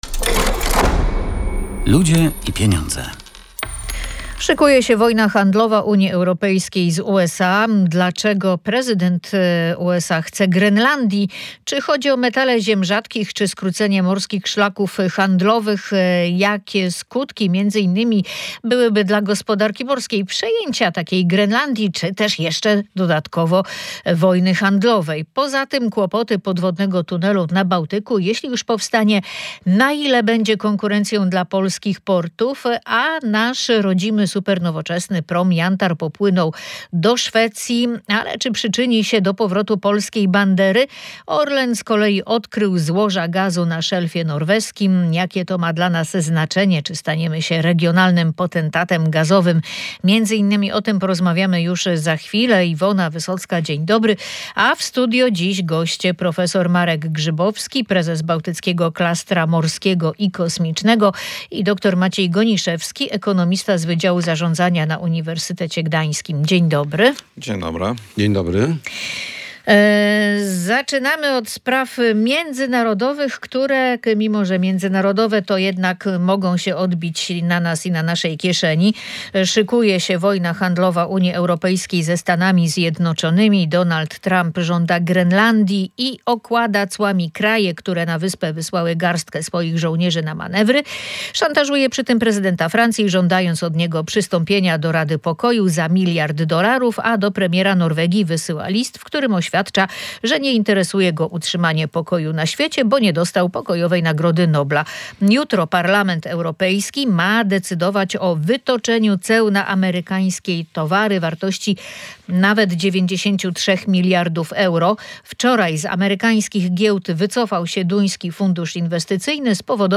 Oceniają eksperci